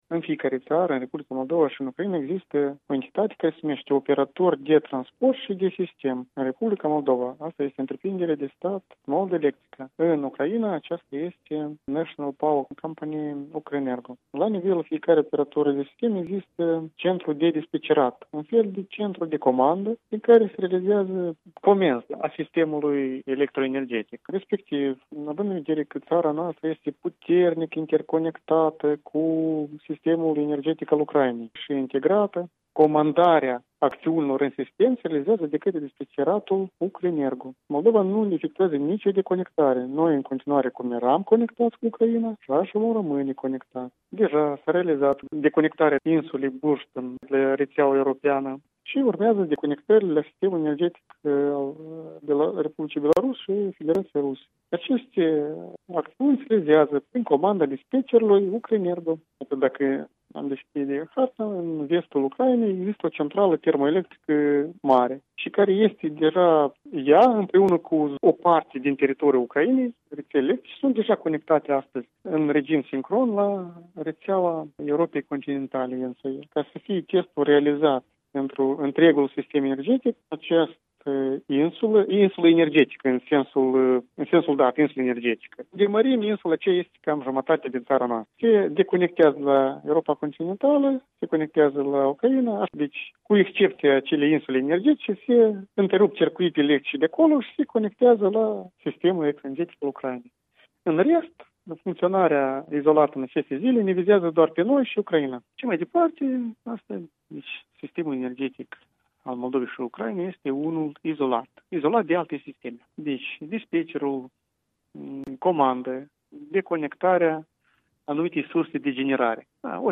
Secretarul de stat Constantin Borosan despre „testul” energetic